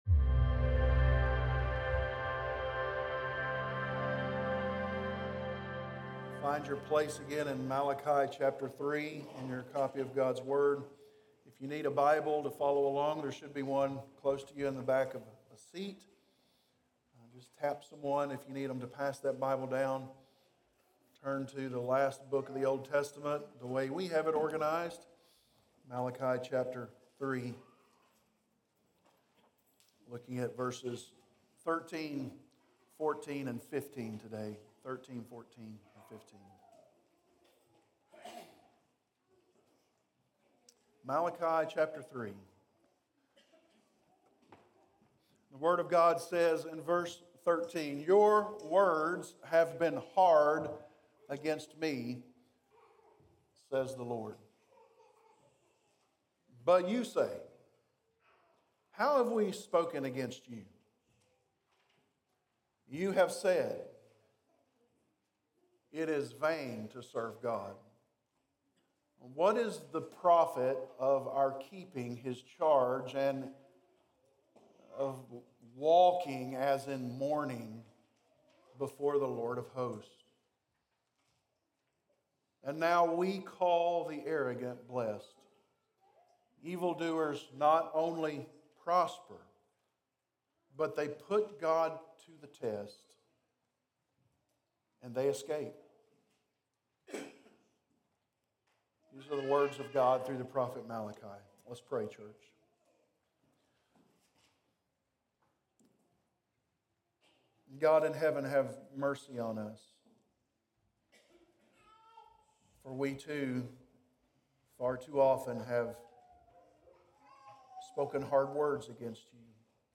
preaches through Malachi